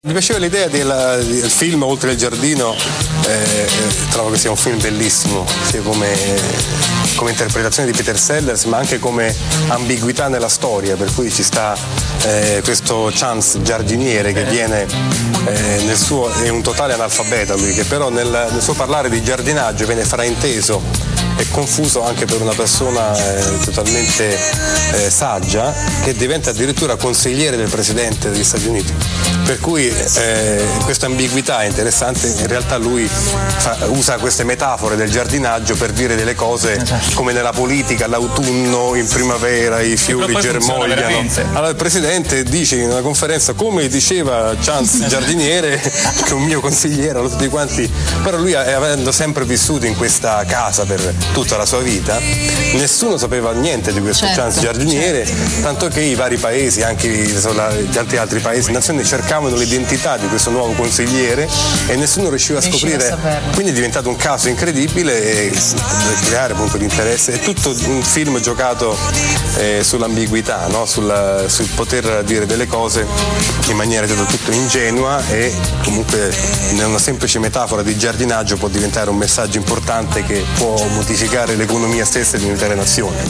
Ma sentite cosa ne pensa  Max, direttamente della sua voce, in questo estratto dalla puntata di MTV Select del 25 ottobre